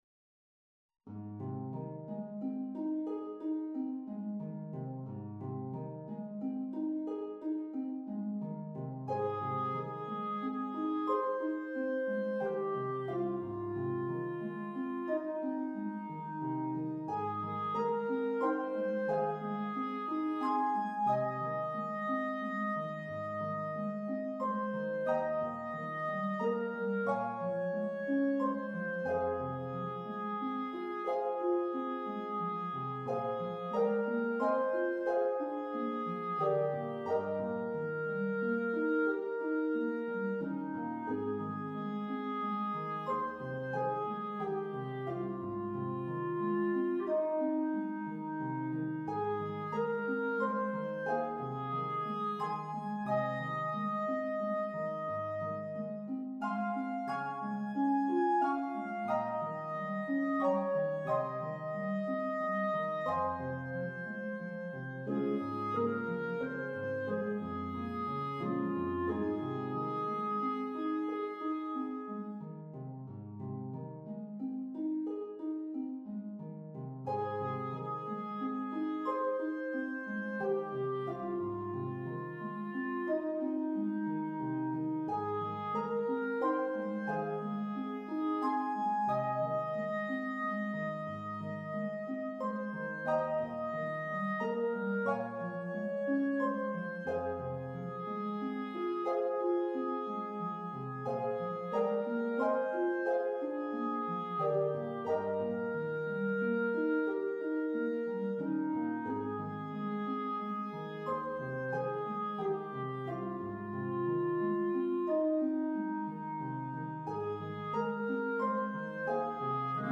for Bb clarinet and piano